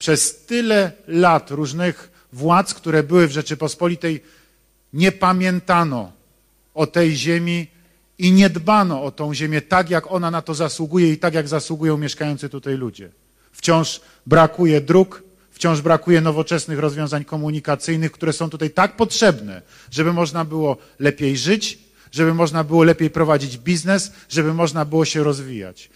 Prezydent Andrzej Duda 22 października w hali biłgorajskiego Ośrodka Sportu i Rekreacji spotkał się z mieszkańcami miasta i powiatu.